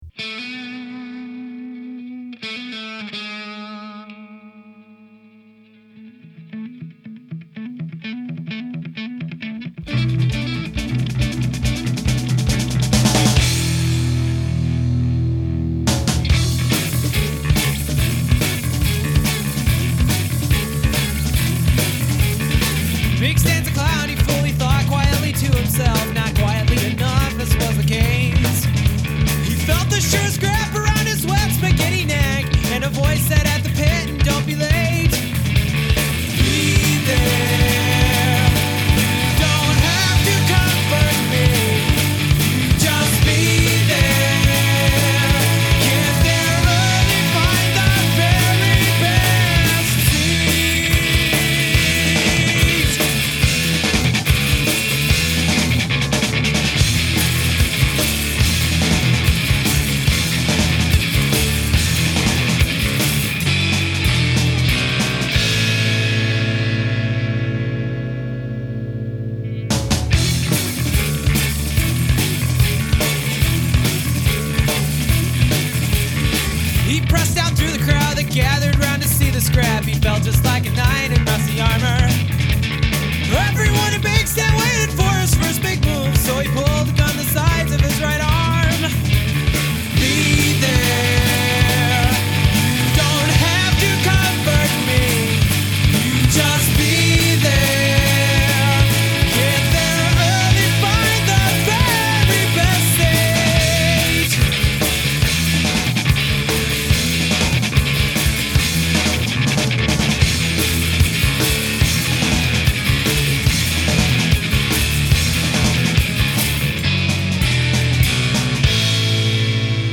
second guitar